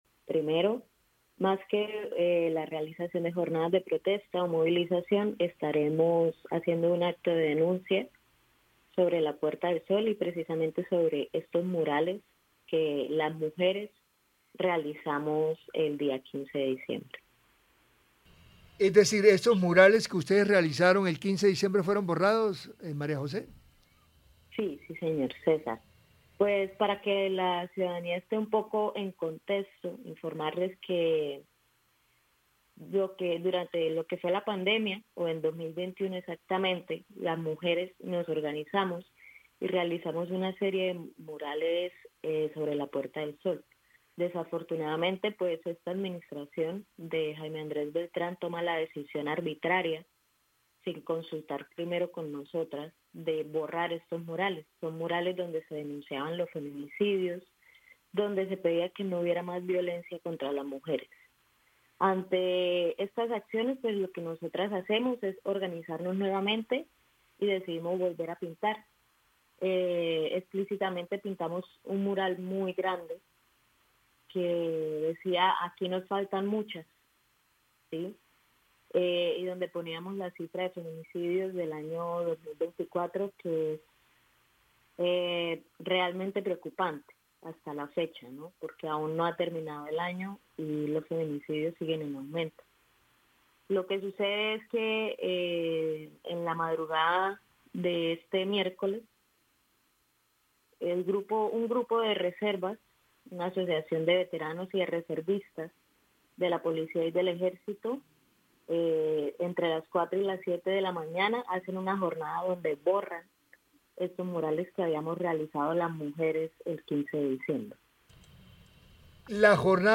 Voz mujeres murales